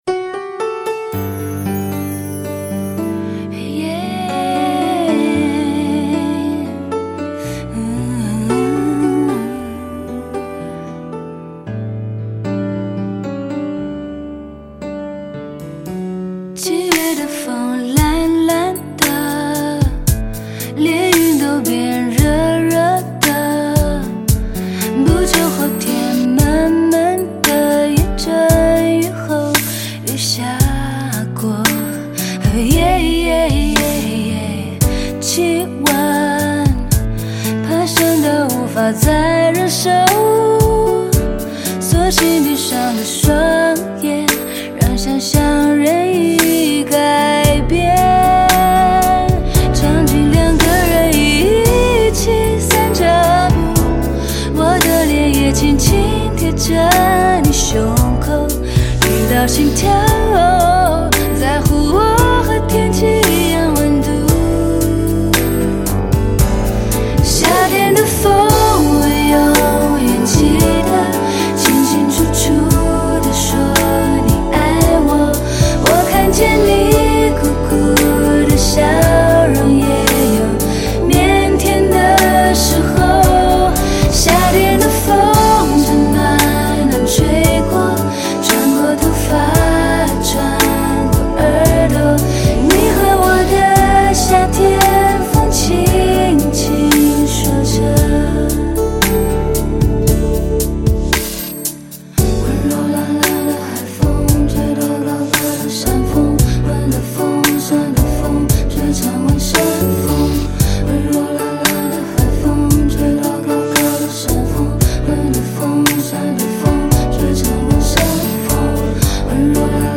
4/4 60以下
无鼓伴奏